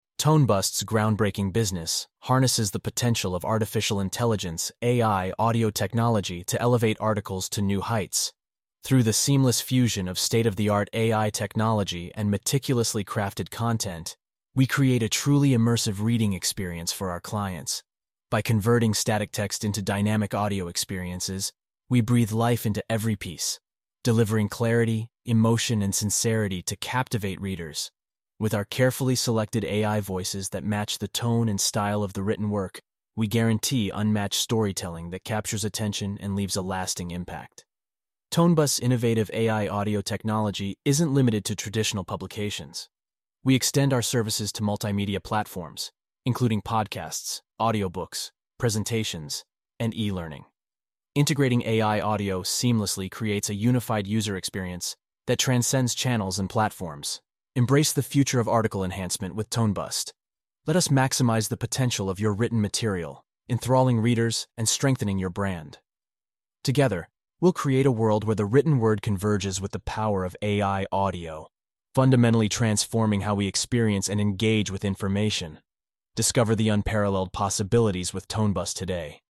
Unleash the Power of AI Voiceover!